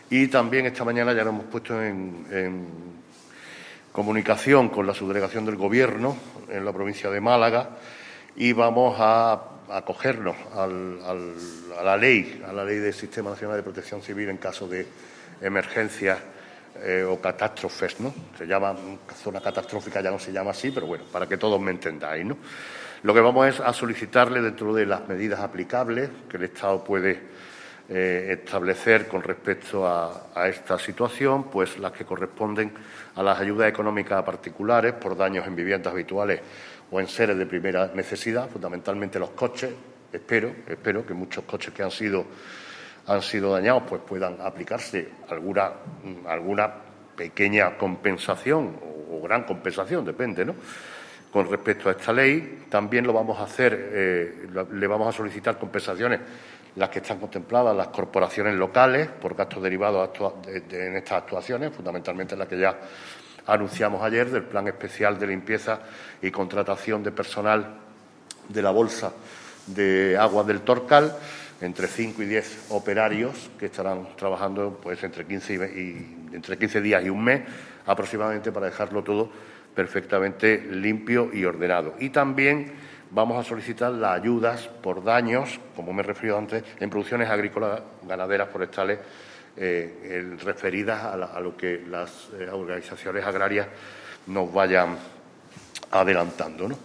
El alcalde de Antequera, Manolo Barón, ha comparecido en la mañana de hoy ante los medios de comunicación para realizar una primera valoración oficial sobre los efectos de la tormenta de granizo que, literalmente, "azotó" a nuestra ciudad en la tarde de ayer en lo que el propio Alcalde calificaba como "la mayor granizada del siglo".
Cortes de voz